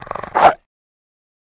Звуки рычания собаки
Ррррр-гав гав ррр